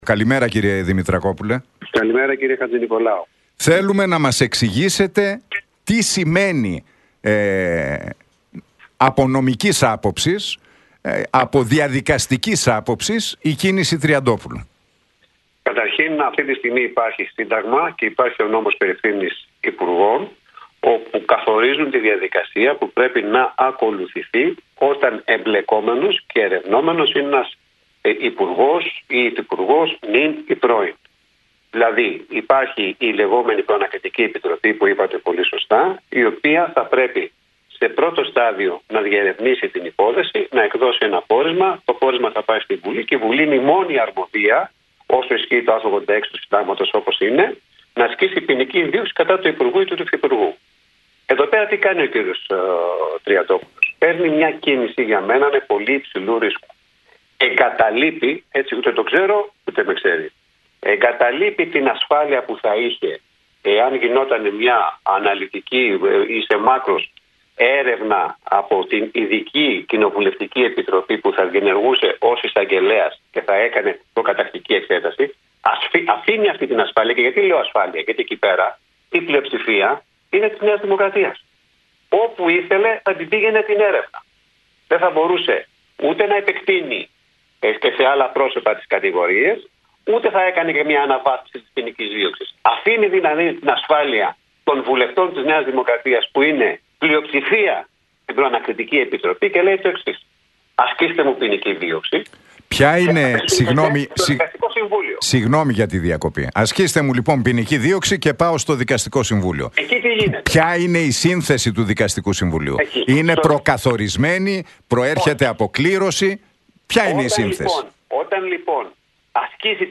Για «κίνηση πολύ υψηλού ρίσκου» κάνει λόγο ο δικηγόρος, Μιχάλης Δημητρακόπουλος μιλώντας στον Realfm 97,8 και την εκπομπή του Νίκου Χατζηνικολάου για την πρωτοβουλία του Χρήστου Τριαντόπουλου να ζητήσει να πάει στον φυσικό δικαστή.